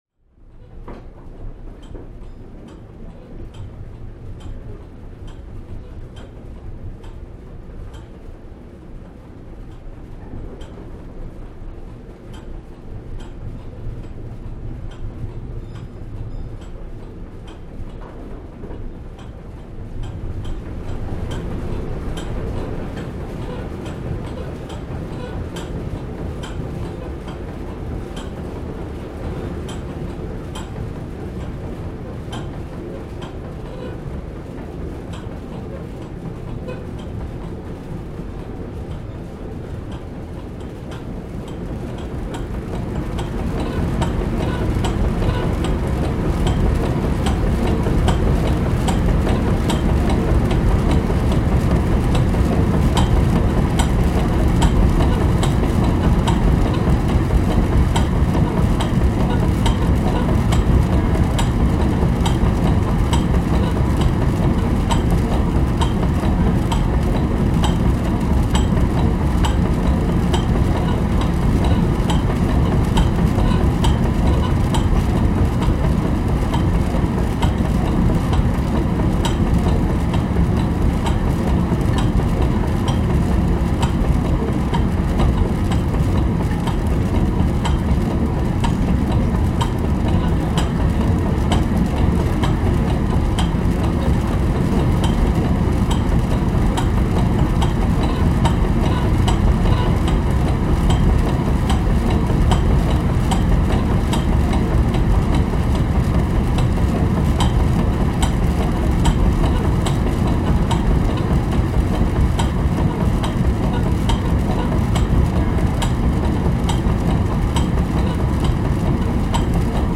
Surround sound mix of location recordings of Wicken Corn Windmil / Denver Mills and Stanton Post Mill whilst in operation.
Stone /  Machine
brixton-2nd-floor-extract.mp3